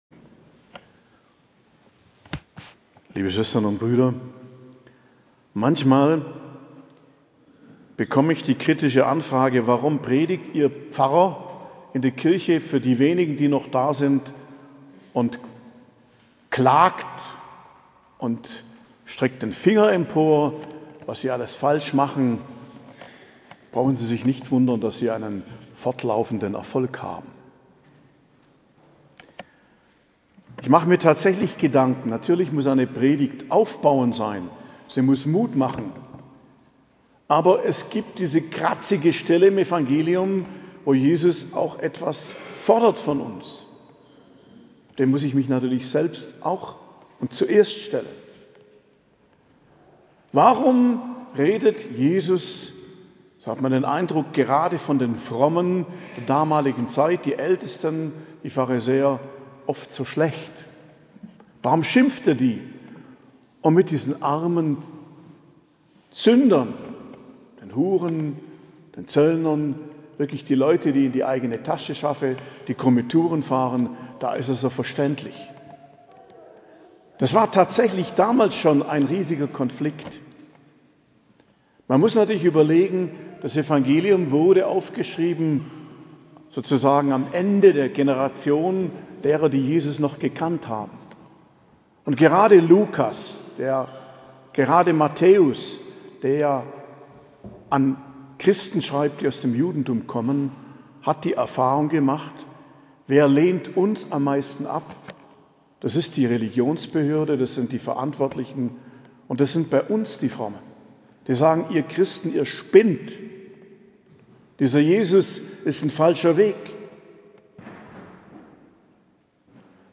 Predigt zum 26. Sonntag i.J., 1.10.2023